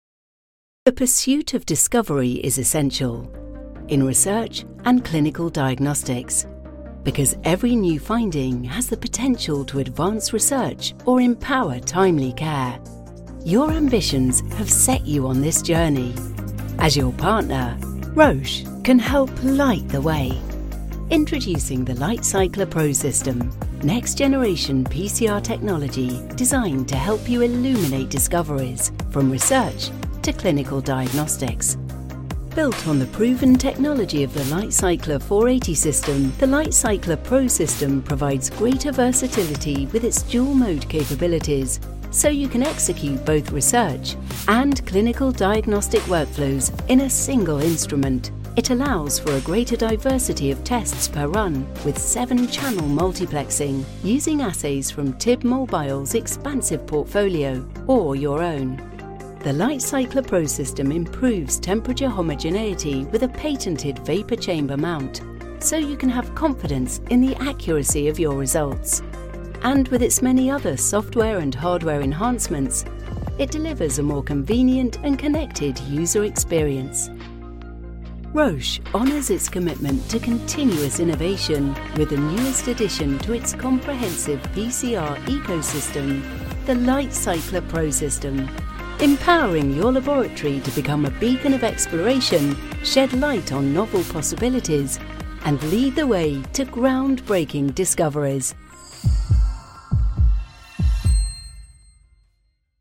English (British)
Commercial, Warm, Versatile, Friendly, Corporate
E-learning